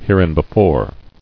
[here·in·be·fore]